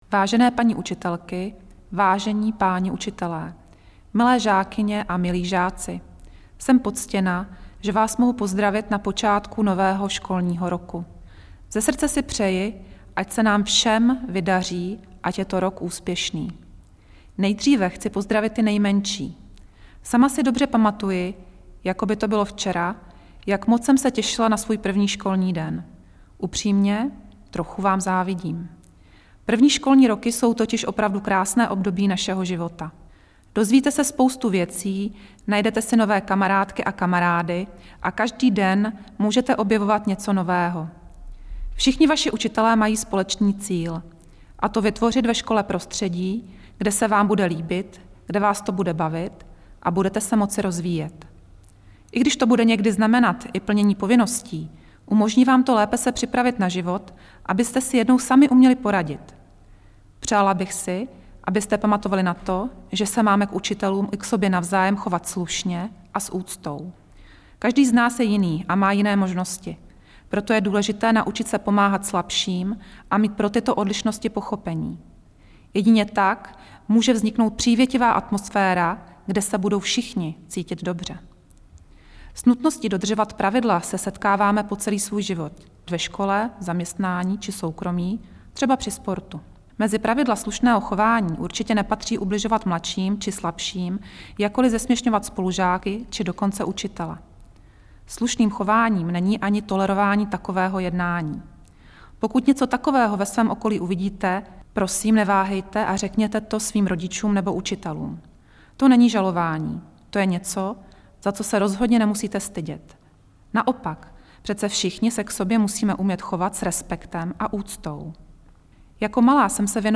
Projev ministryně školství k novému školnímu roku, MŠMT ČR
Projev ministryně Kateřiny Valachové k 1. září 2015 v mp3